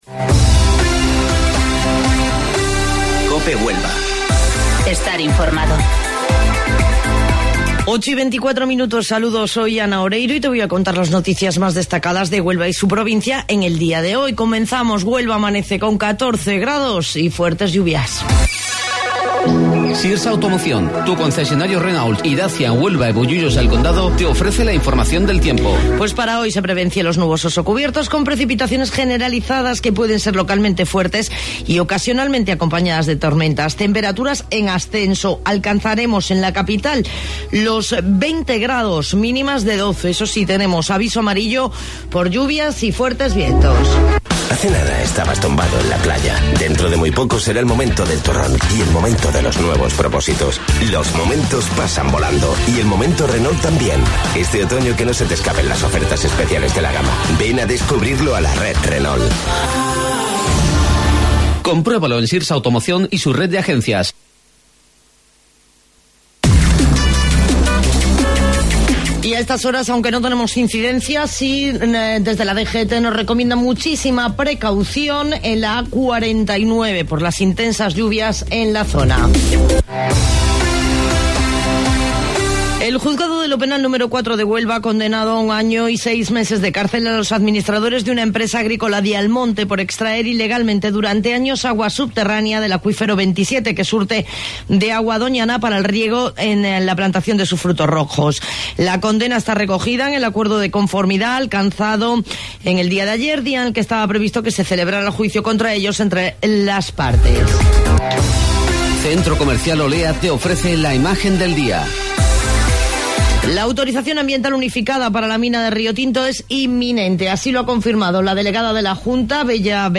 AUDIO: Informativo Local 08:25 del 22 de Noviembre